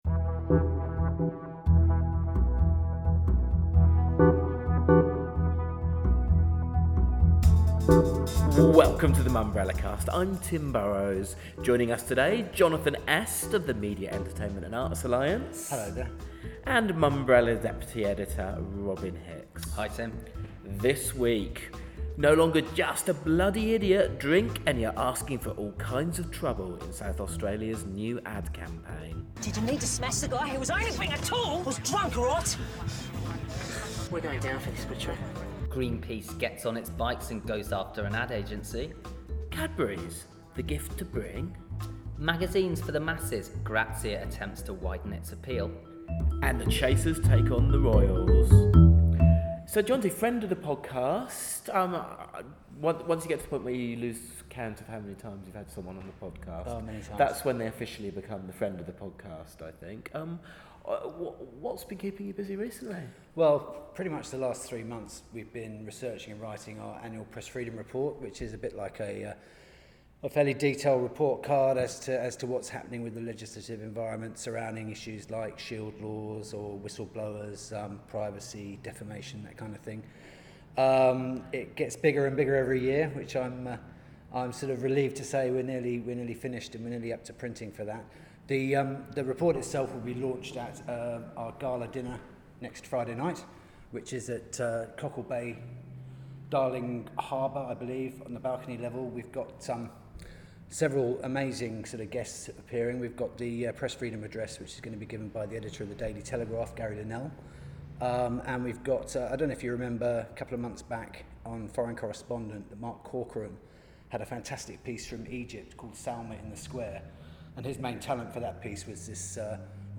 ADVERTISEMENT ( With apologies for some of our more echoey moments – we were test-driving a new peice of equipment ).